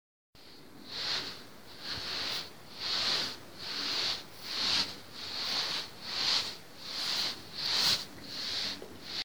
Barriendo
Grabación sonora en la que se escucha el sonido de alguien barriendo con un cepillo o escoba.
Sonidos: Acciones humanas
Sonidos: Hogar